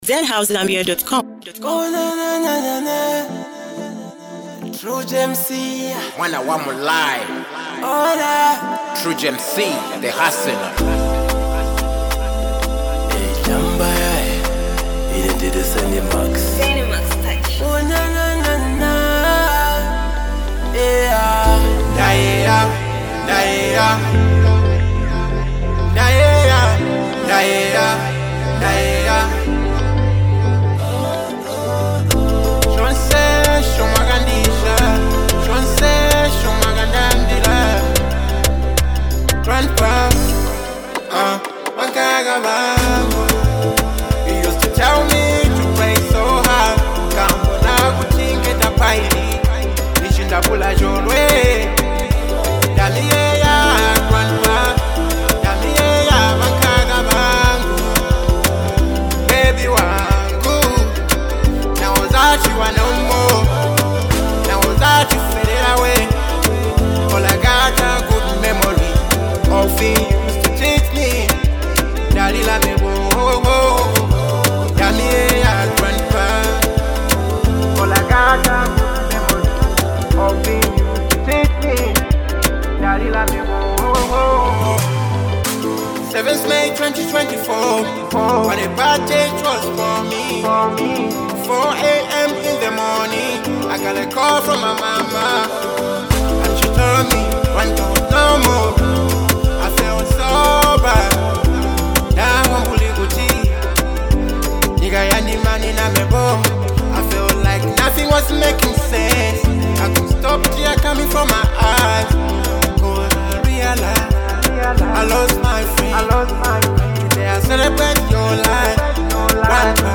a heartfelt sound filled with real emotion and soul.